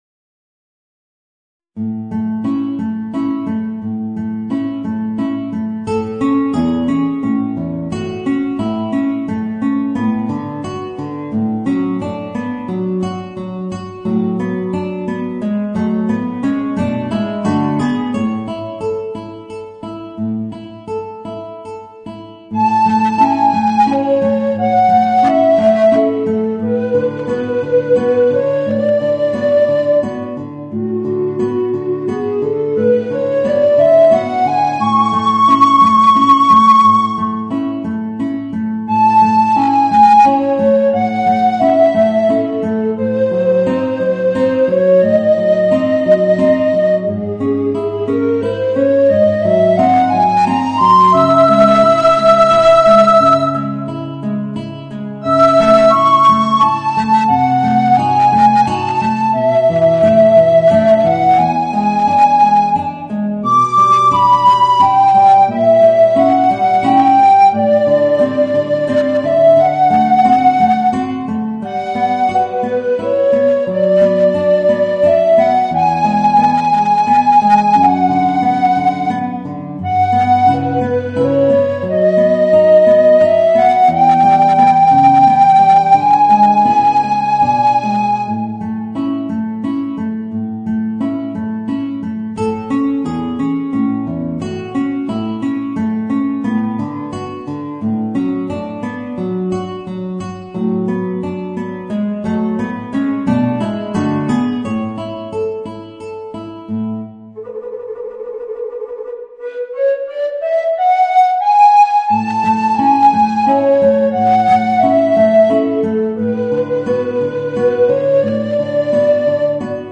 Voicing: Alto Recorder and Guitar